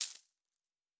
pgs/Assets/Audio/Fantasy Interface Sounds/Coins 08.wav at master
Coins 08.wav